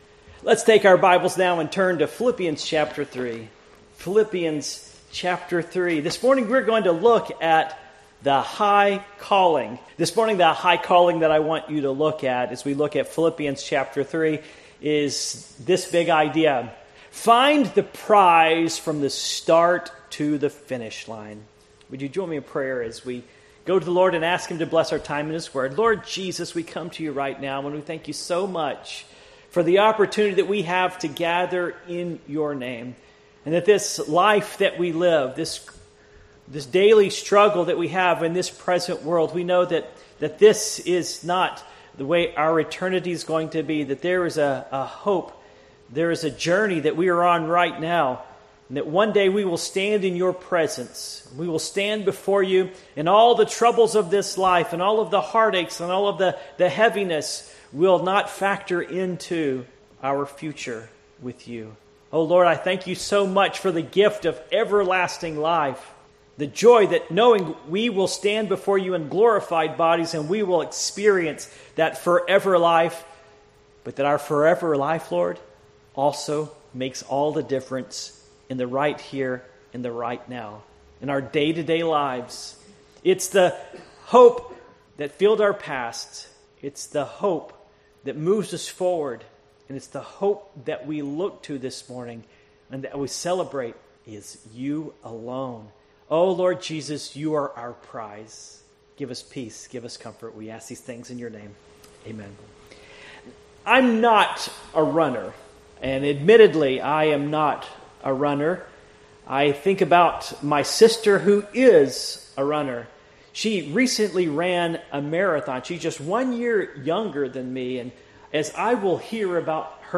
Passage: Philippians 3:12-14 Service Type: Morning Worship